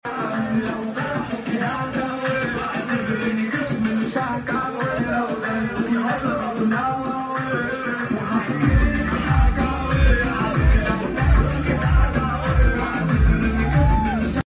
أسماء جلال بتغني مع توليت sound effects free download